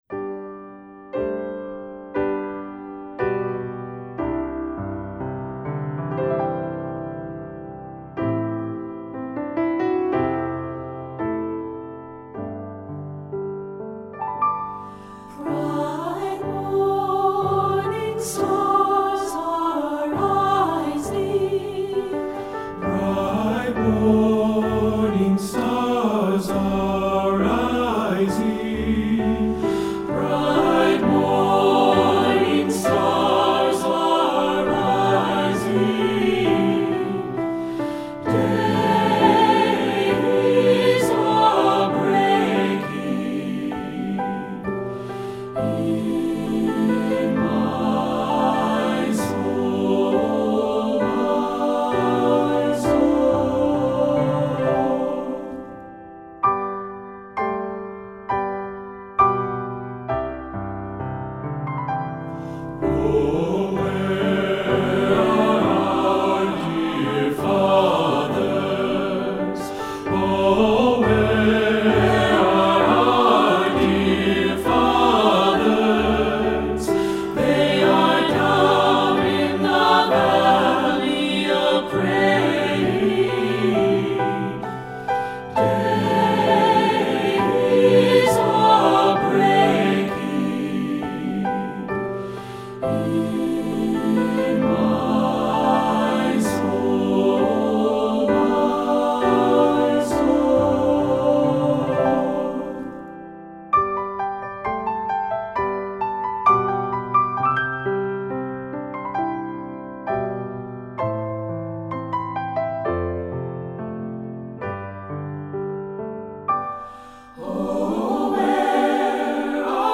Composer: Appalachian Folk Song
Voicing: SATB